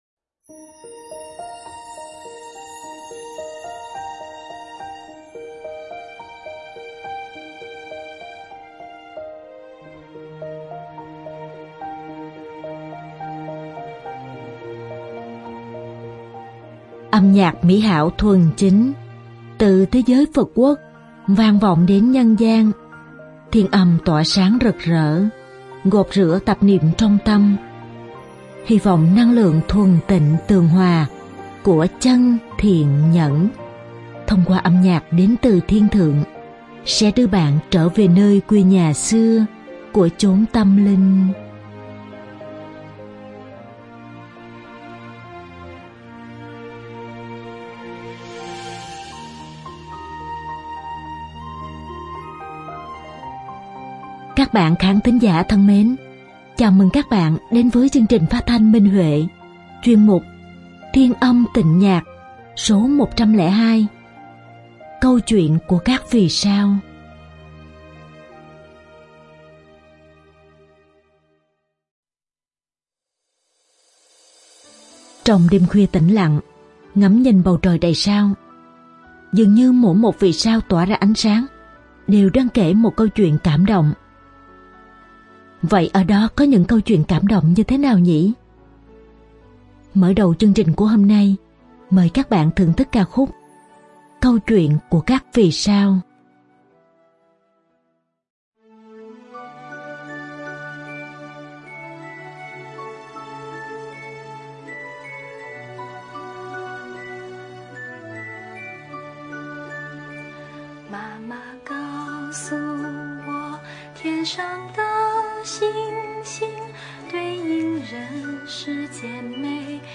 Đàn dương cầm